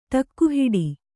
♪ ṭakku hiḍi